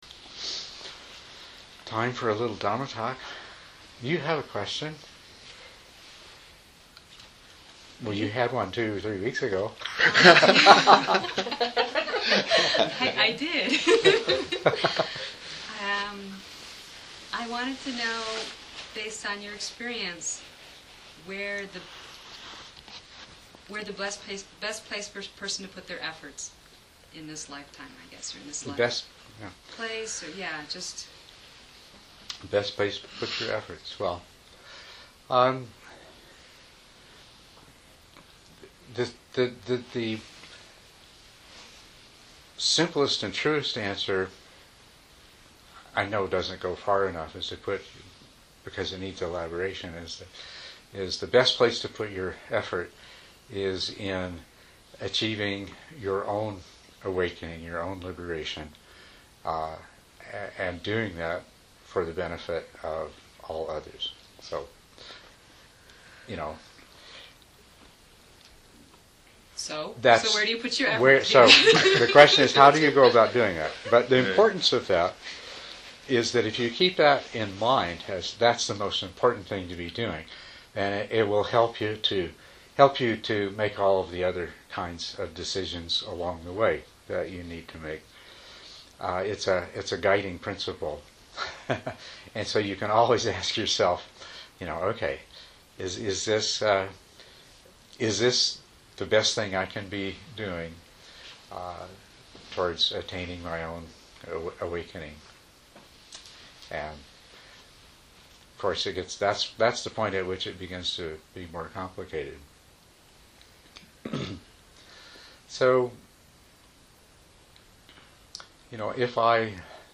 Edit talk Download audio (mp3) Download original audio Listen to original audio * Audio files are processed to reduce background noise, and provide (much) better compression.